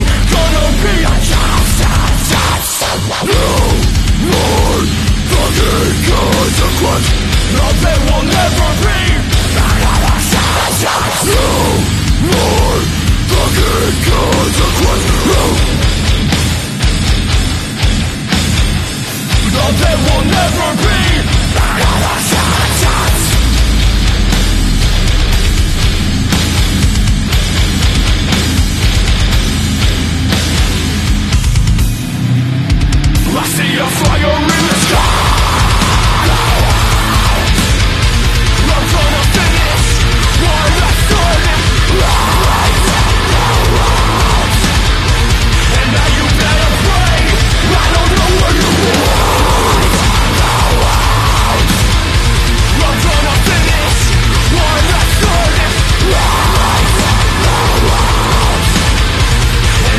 metalcore